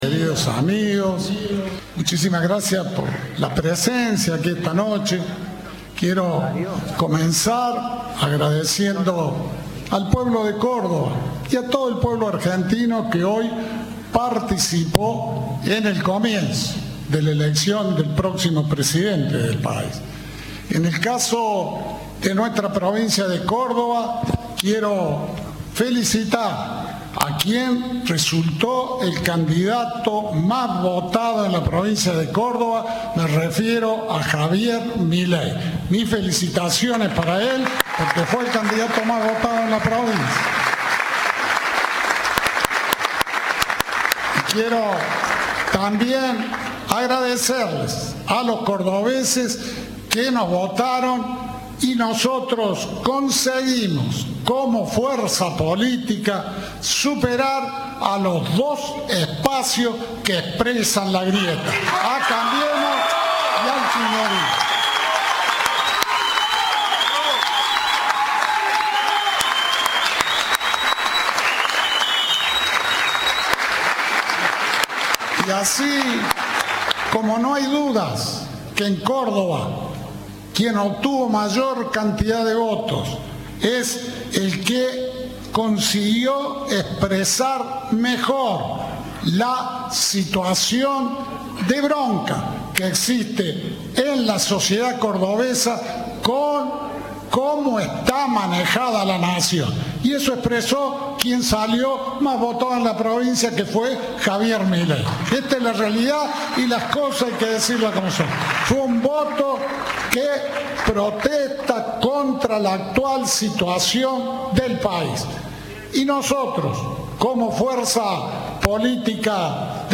"Quiero felicitar al candidato que resultó el candidato más votado en la provincia me refiero a Javier Milei. Mis felicitaciones para él porque fue el candidato más votado en la provincia", sostuvo Schiaretti en el búnker en el que se mostró con su compañero de fórmula Florencio Randazzo.